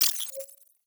Robotic Game Notification 2.wav